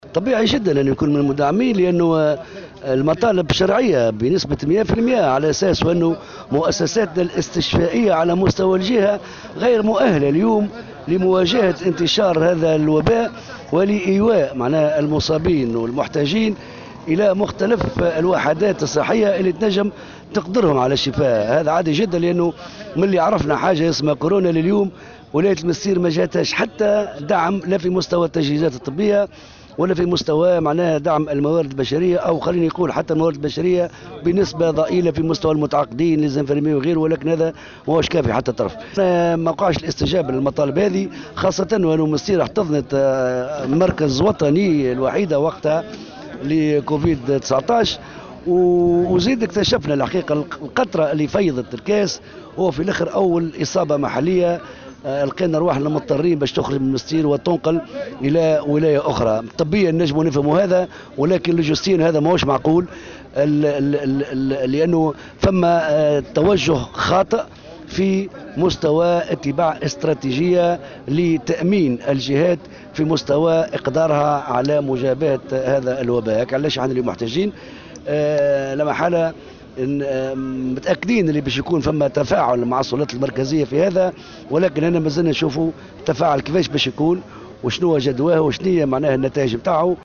وعبّر رئيس بلدية المنستير، منذر مرزوق عن تضامنه مع المعتصمين، واصفا مطالبهم بالشرعية.